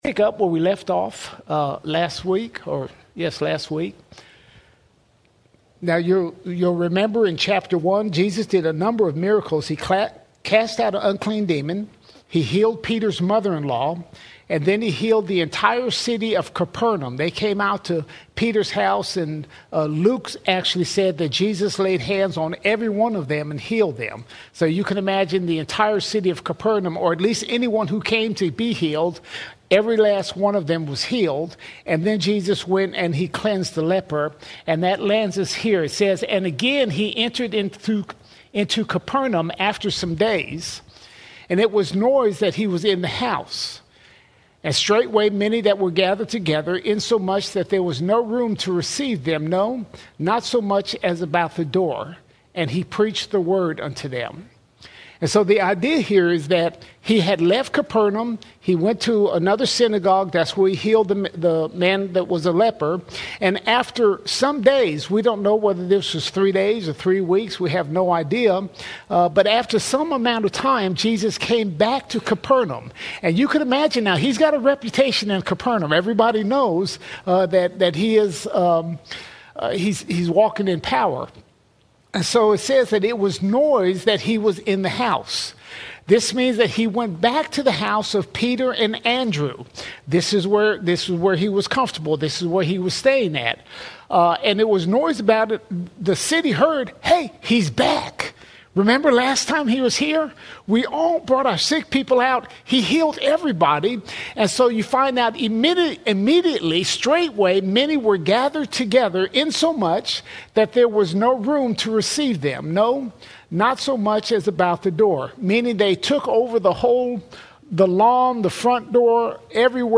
16 October 2025 Series: Gospel of Mark All Sermons Mark 2:1 - 3:12 Mark 2:1 – 3:12 Jesus reveals His authority to forgive sins, heal the sick, and call sinners to Himself.